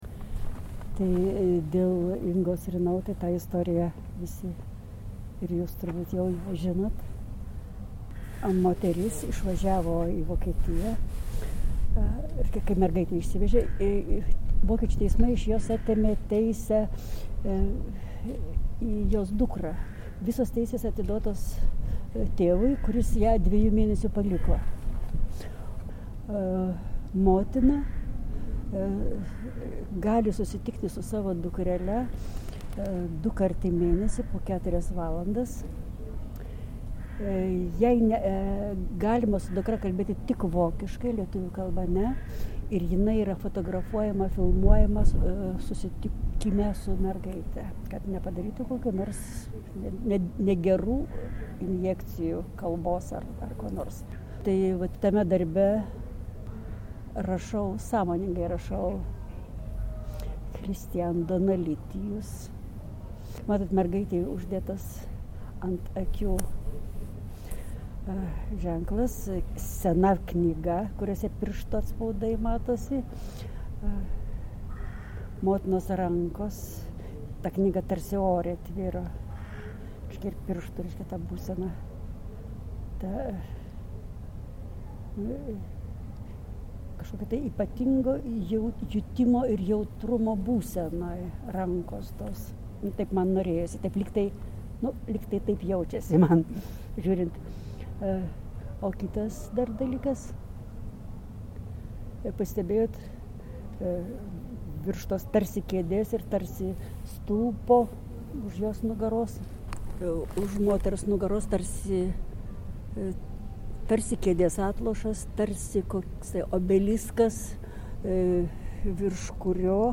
pokalbis lauke